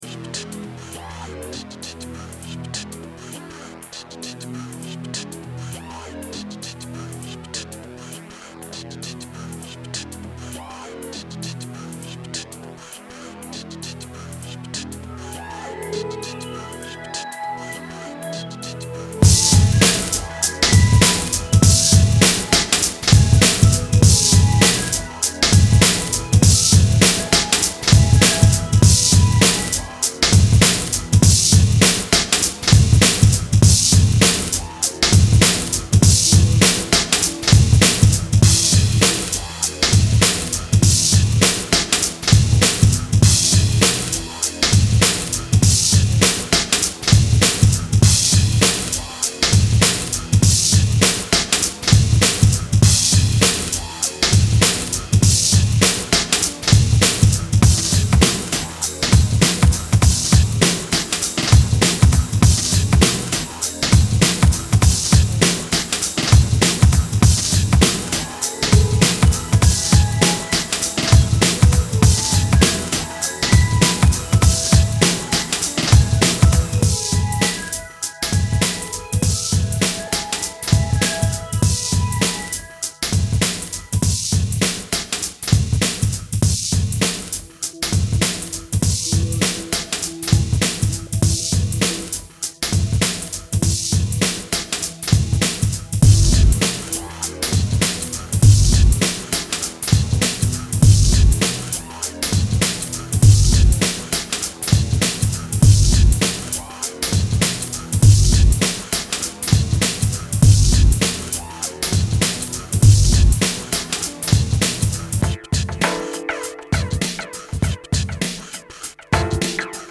Welcome-to-this-moment-Instrumental-loops-wav.mp3